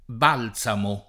Balzamo [ b # l Z amo ]